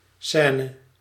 scène[m] BrE square